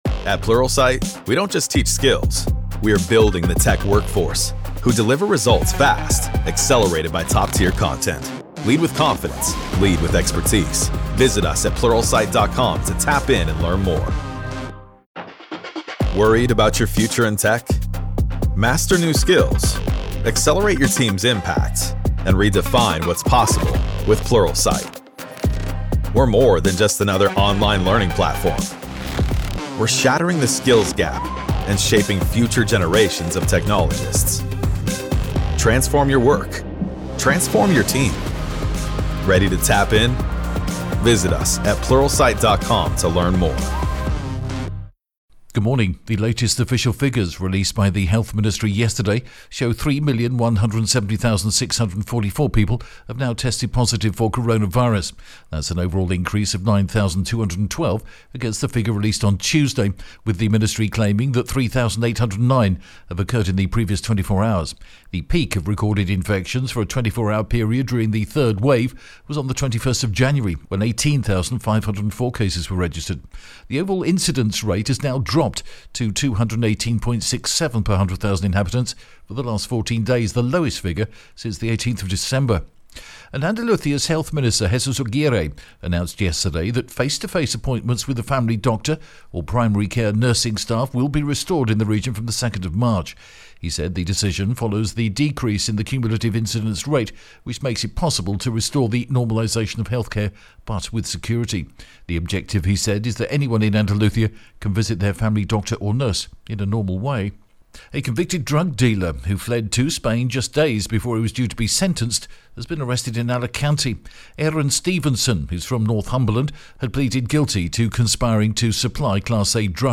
The latest Spanish news headlines in English: 25th February 2021 AM